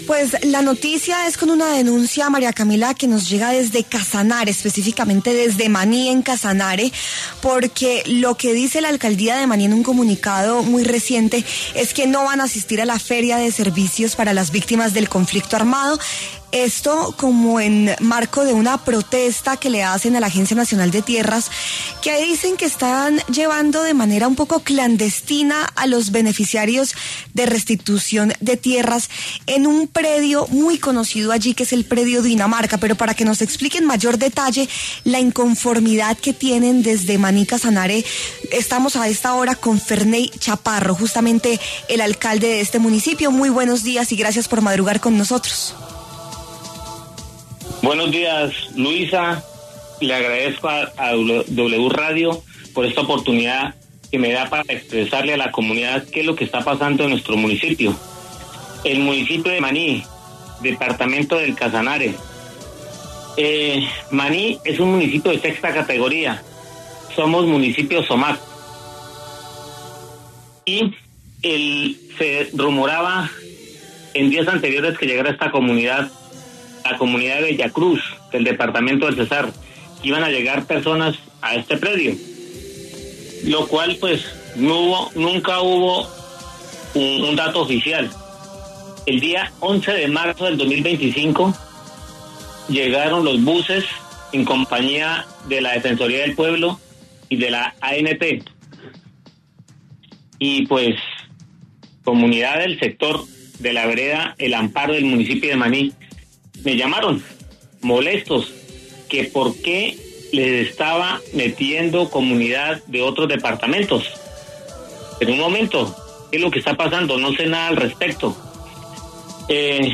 Ferney Chaparro, alcalde de Maní, Casanare
Ferney Chaparro, alcalde de Maní, Casanare, pasó por los micrófonos de La W para denunciar la posible crisis humanitaria en el municipio por cuenta del conflicto armado.